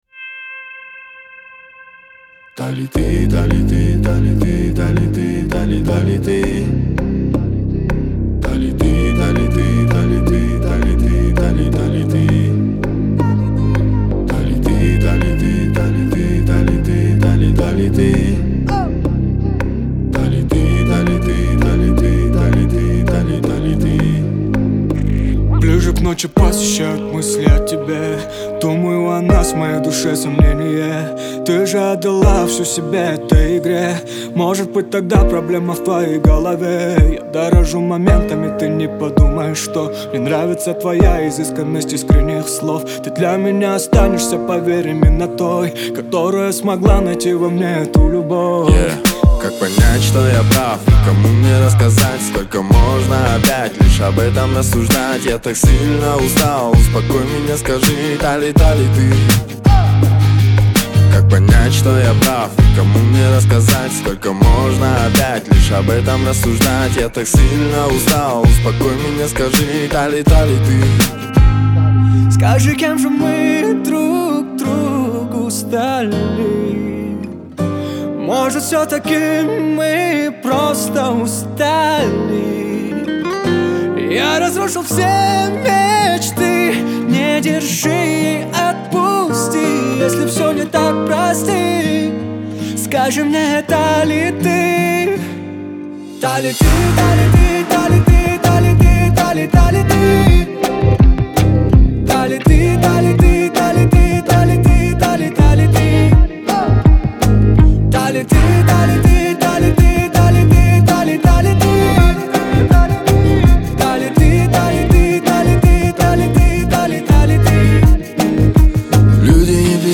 это яркая композиция в жанре поп с элементами фолка